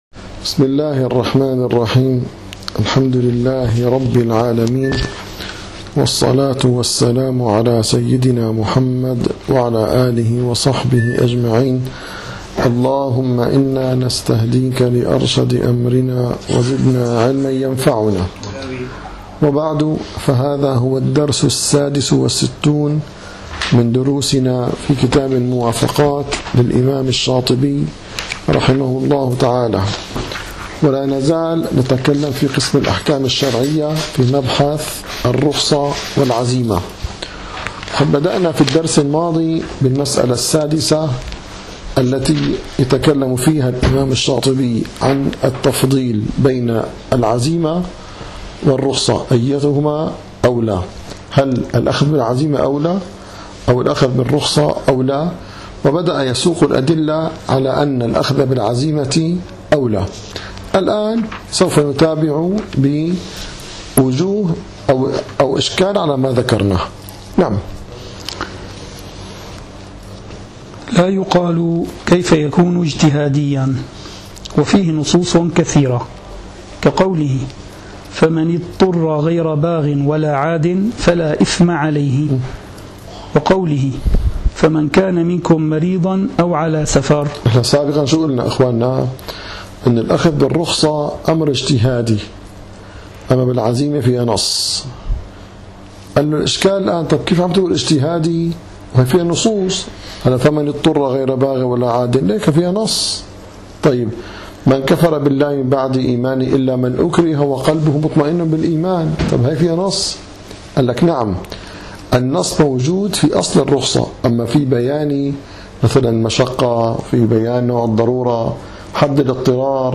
- الدروس العلمية - شرح كتاب الموافقات للشاطبي - 66- قوله لا يقال كيف يكون اجتهادياً